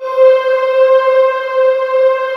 Index of /90_sSampleCDs/USB Soundscan vol.28 - Choir Acoustic & Synth [AKAI] 1CD/Partition D/18-HOLD VOXS